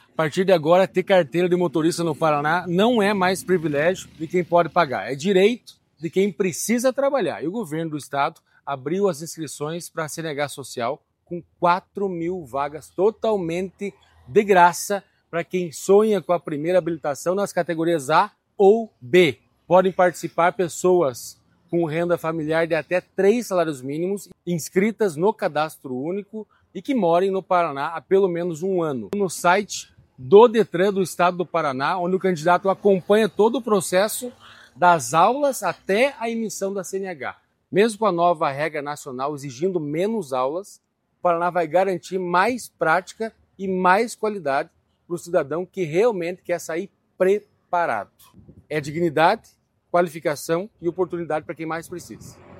Sonora do presidente do Detran-PR, Santin Roveda, sobre a abertura do primeiro edital do programa CNH Social